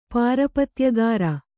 ♪ pārapatyagāra